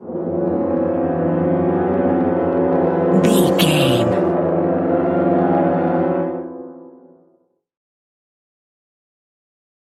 In-crescendo
Thriller
Aeolian/Minor
tension
ominous
eerie
synths
Horror Synths
atmospheres